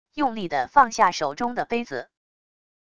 用力的放下手中的杯子wav音频